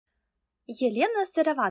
Аудиокнига Как полюбить продавать | Библиотека аудиокниг